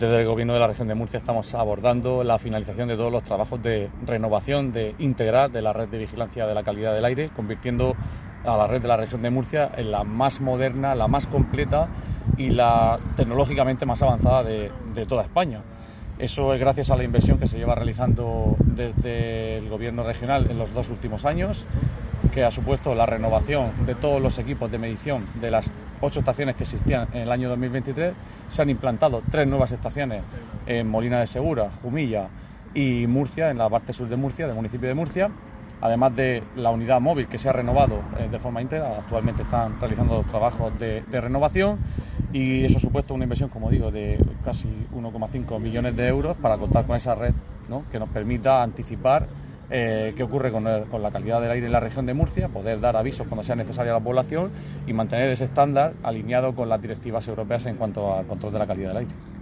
El director general de Medio Ambiente, Juan Antonio Mata, sobre la fase final de la renovación íntegra de la red de vigilancia de calidad del aire de la Región de Murcia.